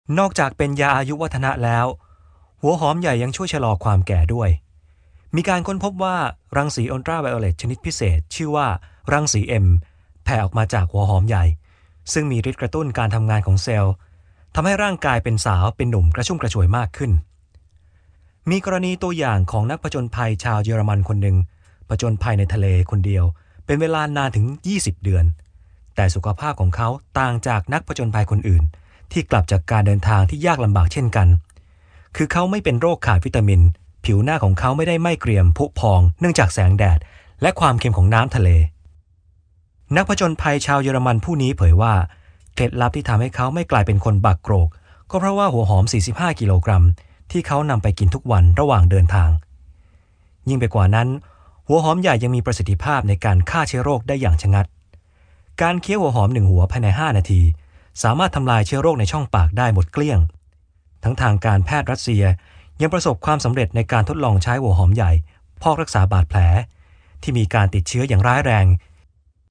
Masculino
I am a professional voice over artist.